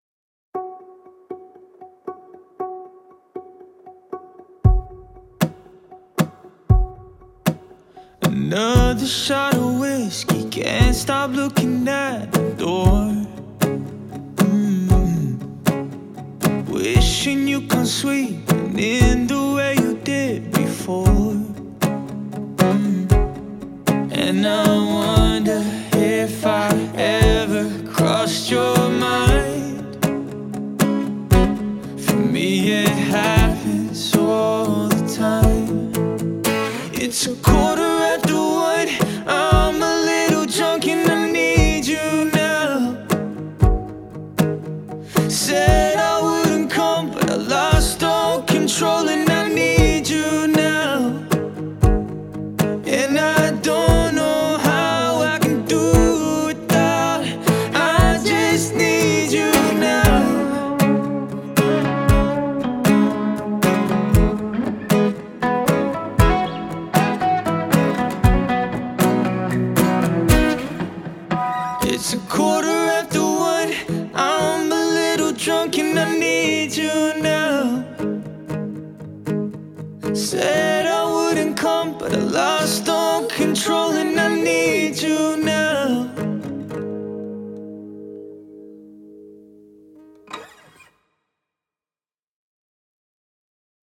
Dual Vocals | Guitar | Looping | DJ